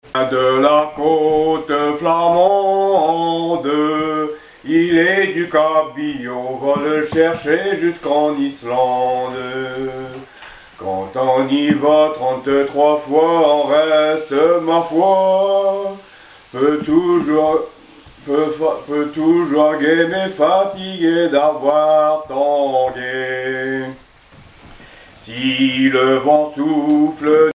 Témoignage et chansons maritimes
Pièce musicale inédite